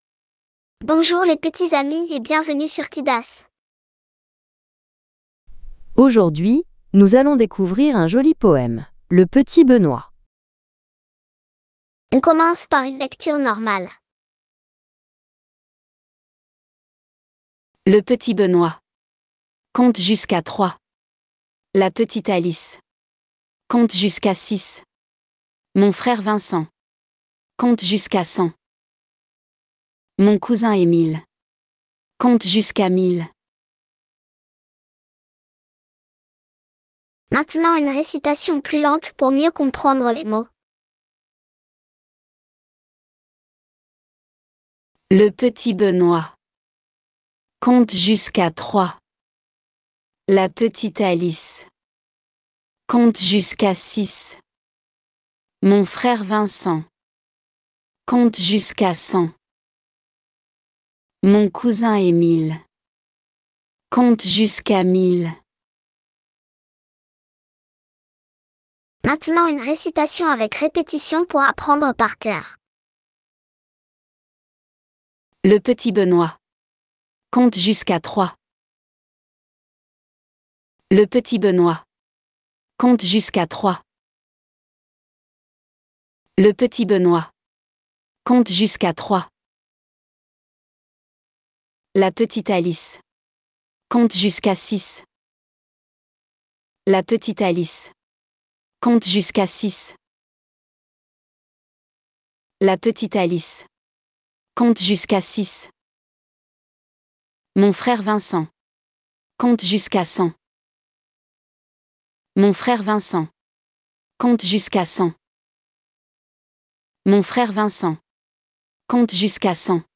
Il y a ici le mp3 du poème avec une prononciation native française .